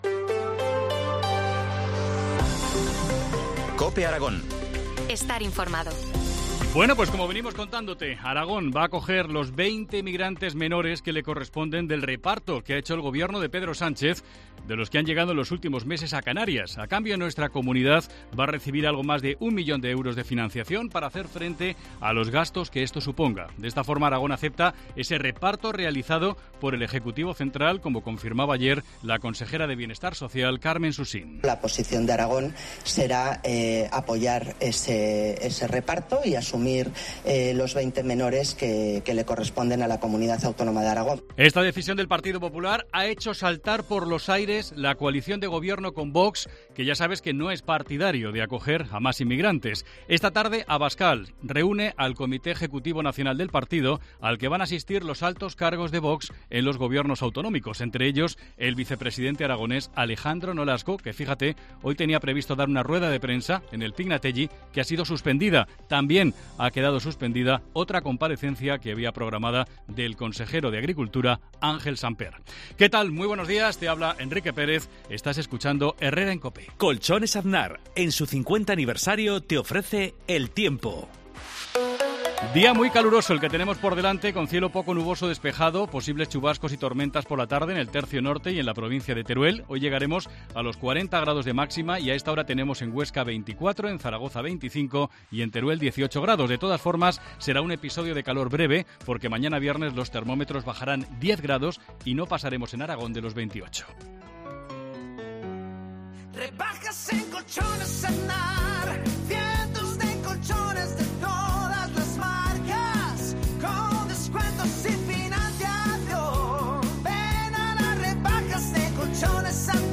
AUDIO: Titulares del día en COPE Aragón